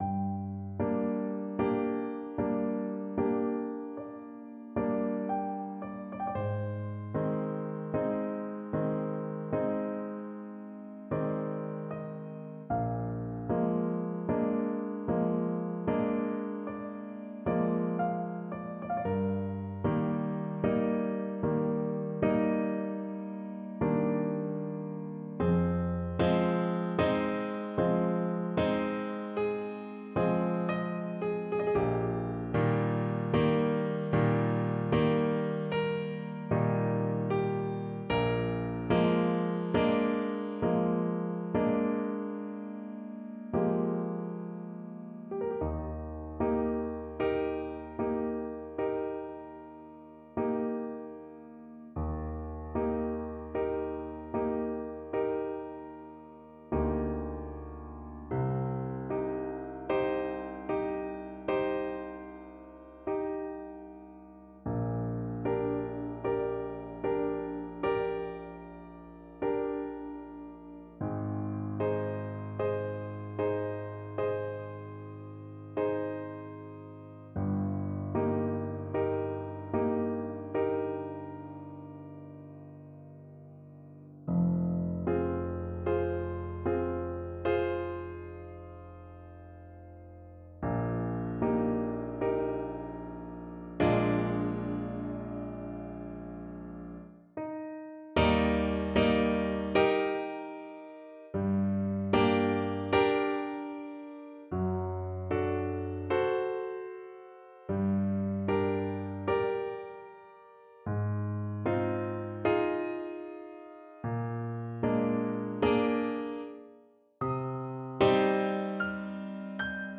Clarinet version
4/4 (View more 4/4 Music)
Classical (View more Classical Clarinet Music)
Neapolitan Songs for Clarinet